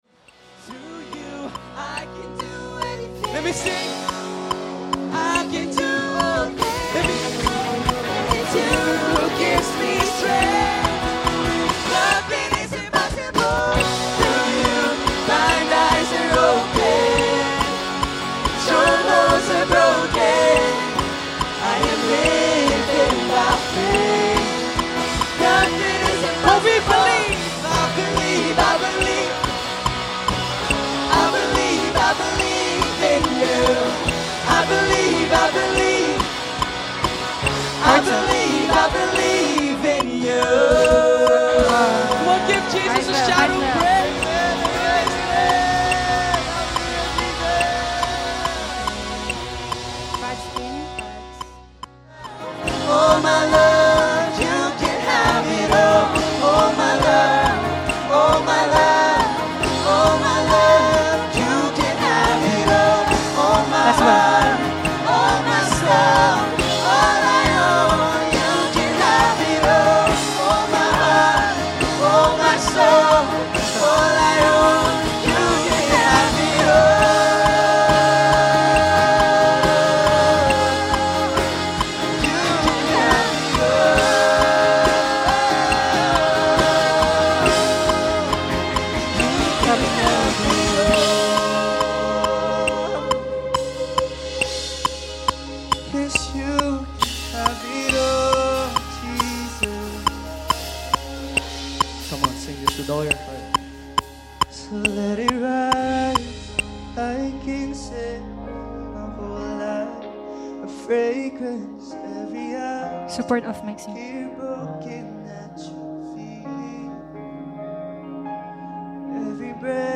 this is basically what you hear in the in-ear mix whenever I'm serving